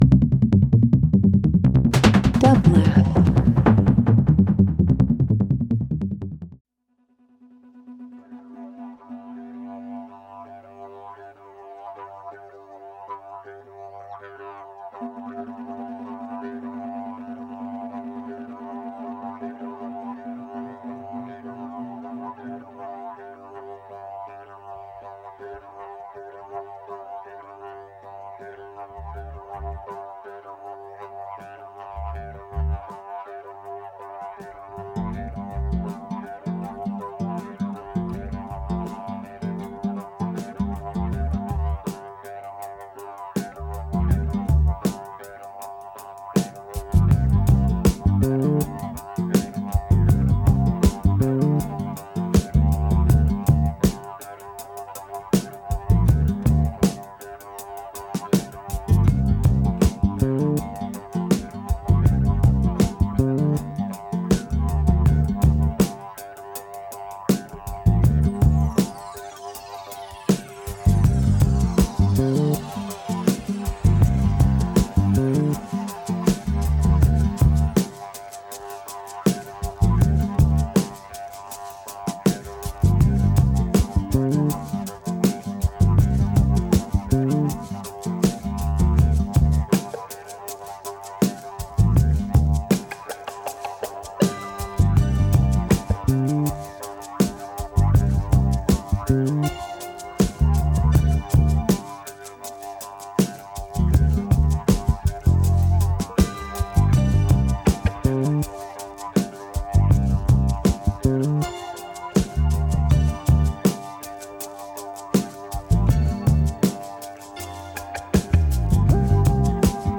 Alternative Electronic Indie Jazz R&B